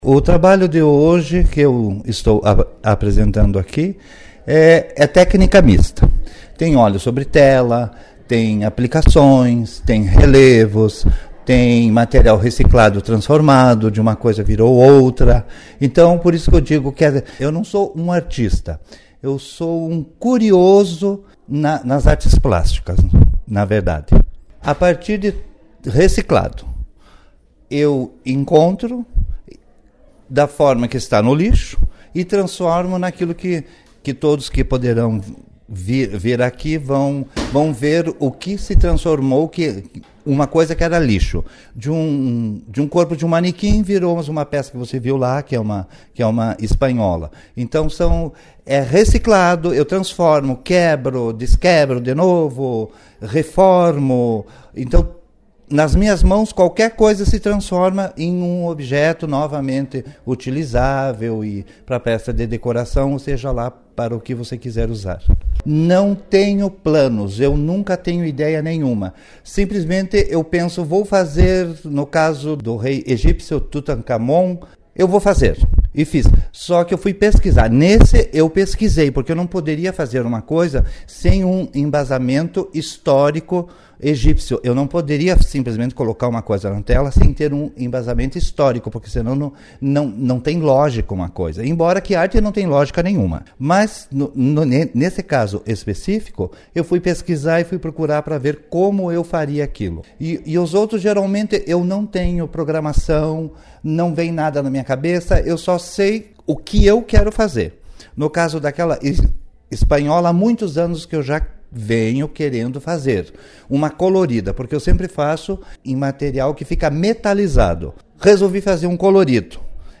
Finalizando as entrevistas para a Colmeia, o artista, com a sua simpatia e encanto de bem com a vida, falou que o que ele faz não é arte, mas sim é curiosidade.